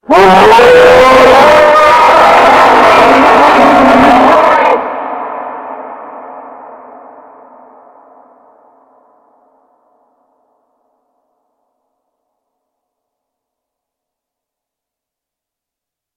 Monster Roar Animal 07 Lower Pitched Sound Effect Download: Instant Soundboard Button